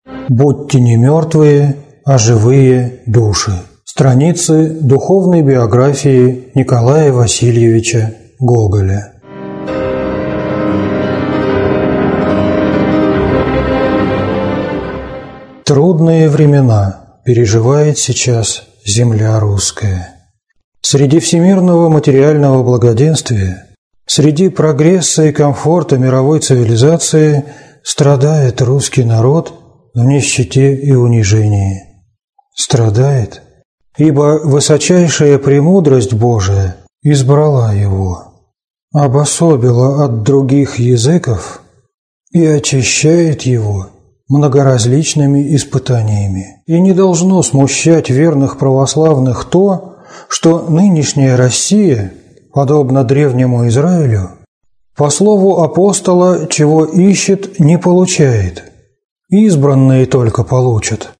Аудиокнига Будьте не мертвые, а живые души | Библиотека аудиокниг